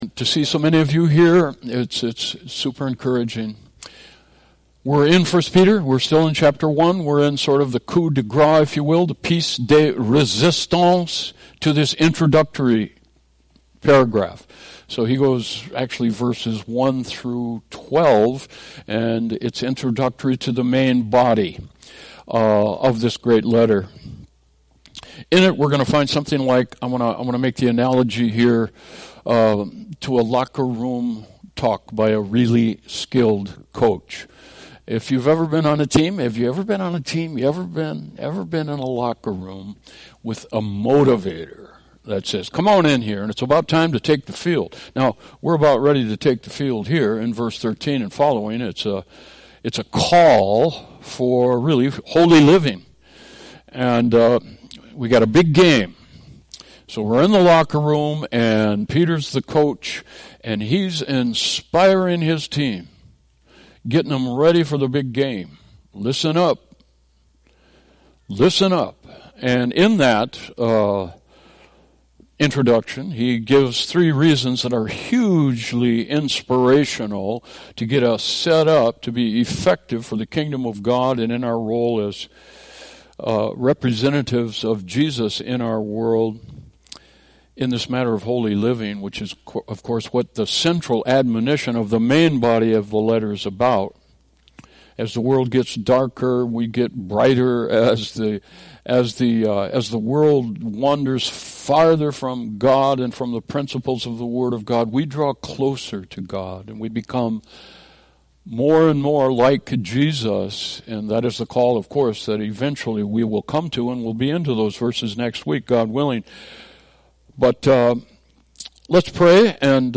Worship Service 6/14/2020
Passage: 1 Peter 1:10-12 Service Type: Worship Service